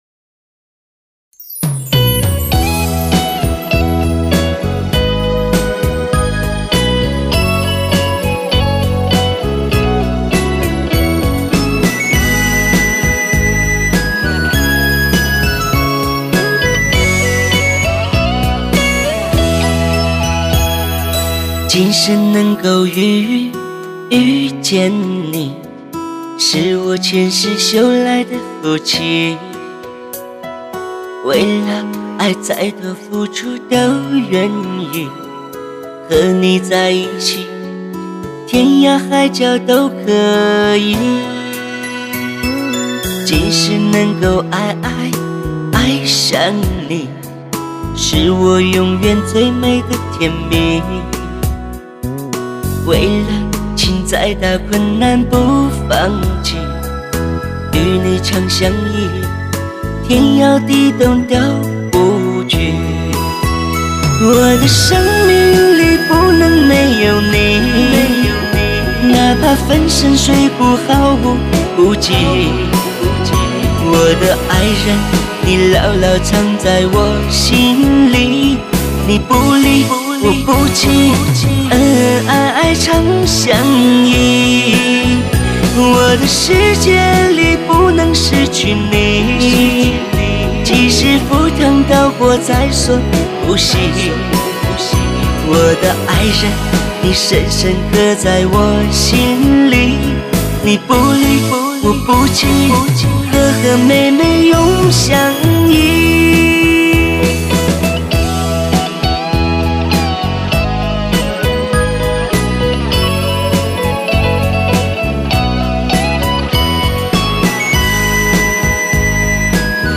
主打歌曲